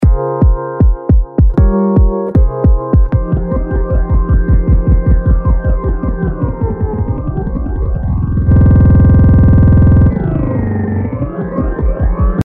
プリセット「Pitch Verb Looper」はHexダイヤル右のONボタンで発動し、とてもいい感じに自然にリピート効果を受けられ、ライブや制作でも使えると思います。
他、「Popping Corn」は、PHASERとROLLの複雑で強烈な効果が素晴らしく、色々な局面での活躍が想像できました。